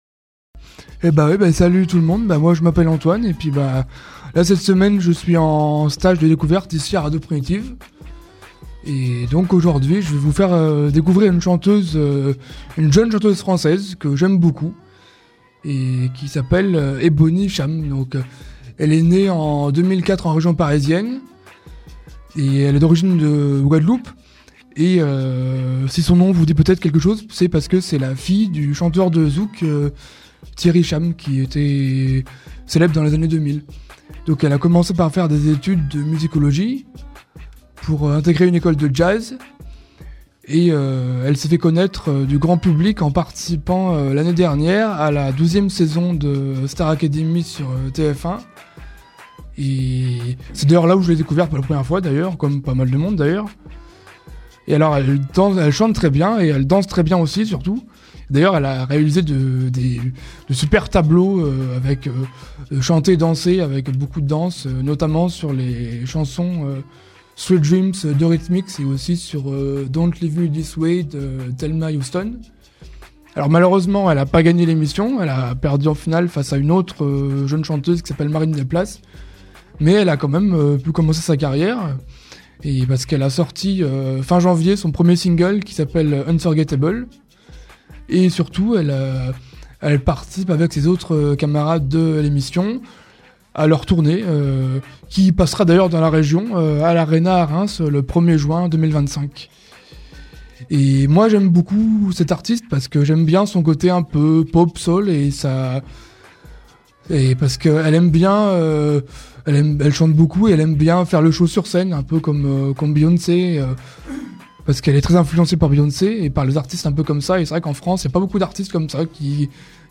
Chronique du 14 mars (7:26)